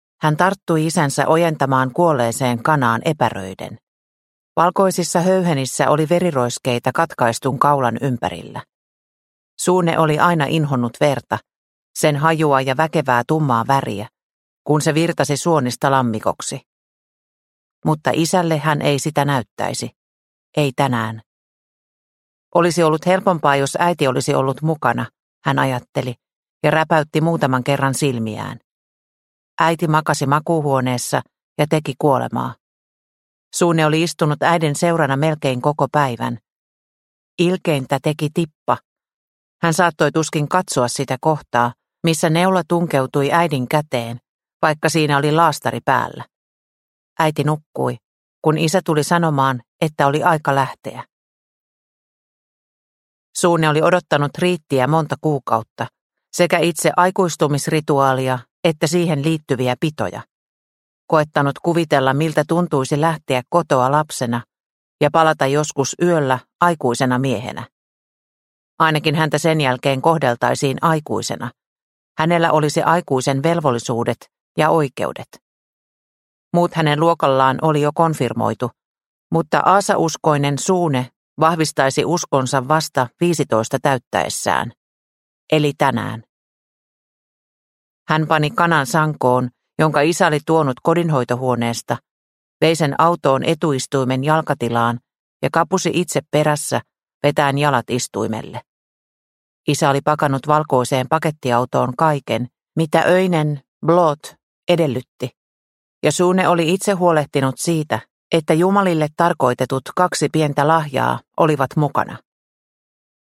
Uhrilehto – Ljudbok – Laddas ner